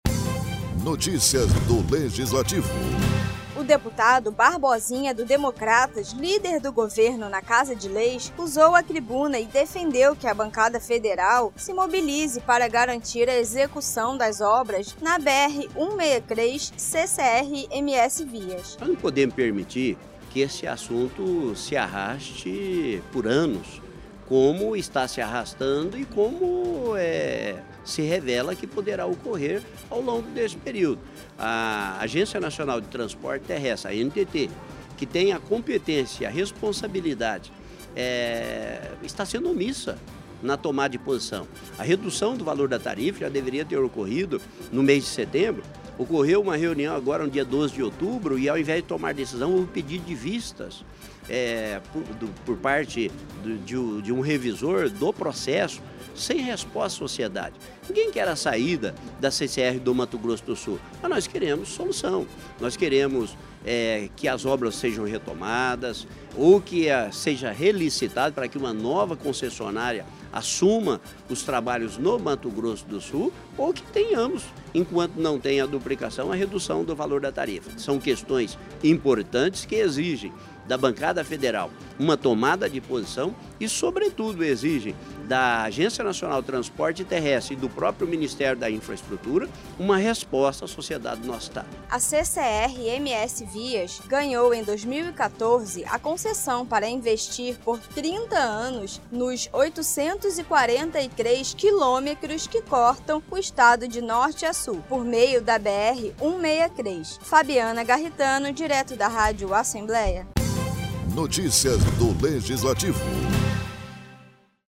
Os deputados estaduais mais uma vez questionaram a falta de cumprimento do contrato de concessão de obras da BR-163, em Mato Grosso do Sul. O deputado Barbosinha, do Democratas, usou a tribuna e definiu como absurda a proposta da concessionária CCR MSVias de diminuir o preço do pedágio de forma diluída ao longo de 25 anos.